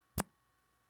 Звук отклеивания упаковки от присоски